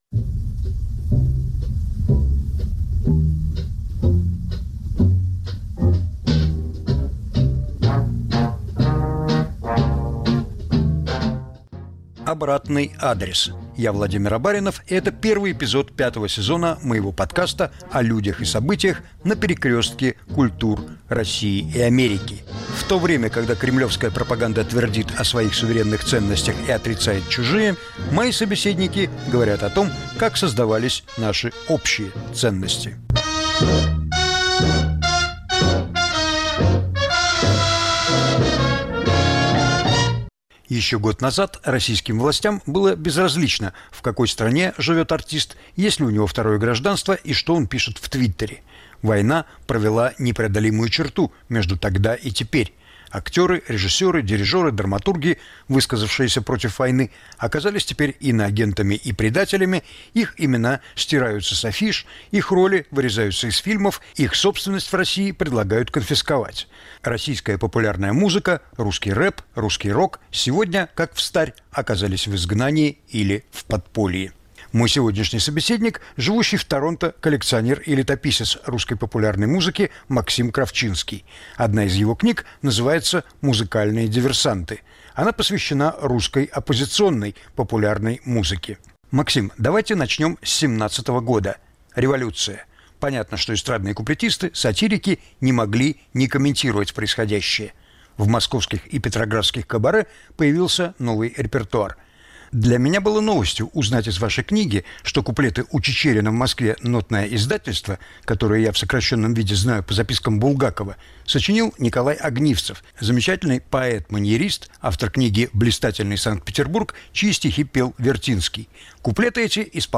Повтор эфира от 10 марта 2023 года.